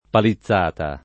[ pali ZZ# ta ]